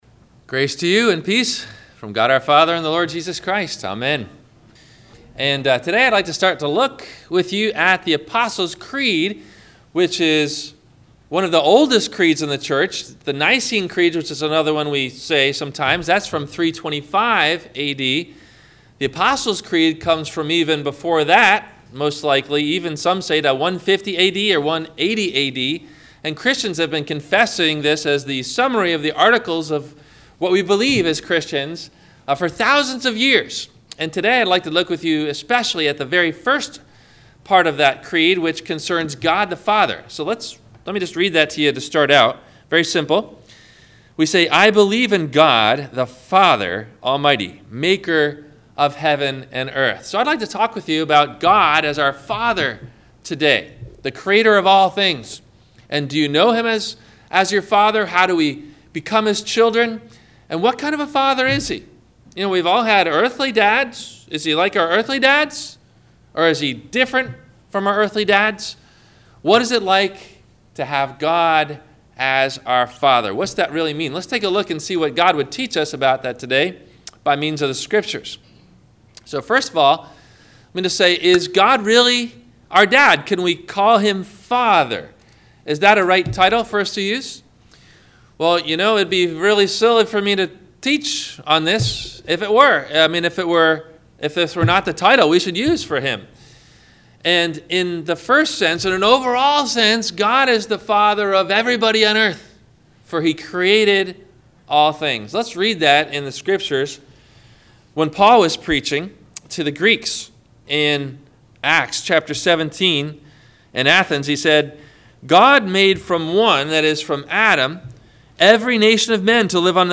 The Apostle's Creed - God the Father - Sermon - June 18 2017 - Christ Lutheran Cape Canaveral